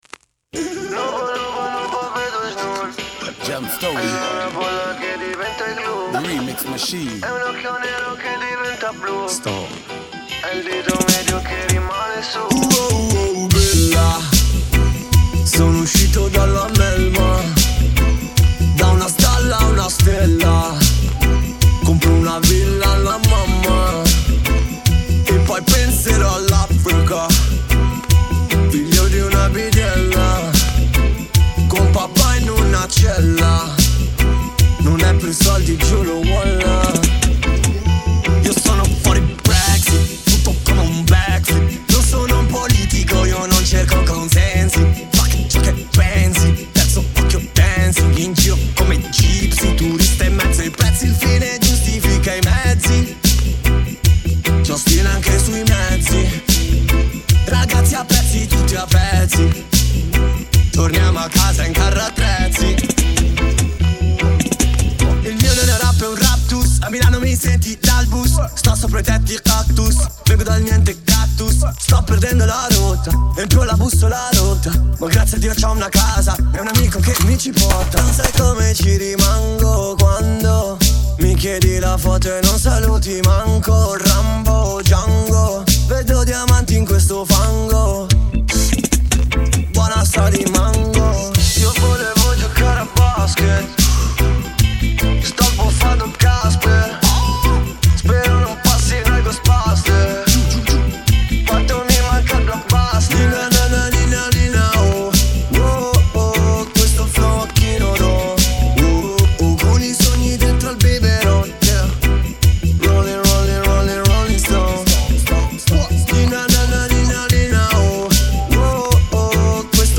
riddim